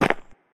step-3.ogg.mp3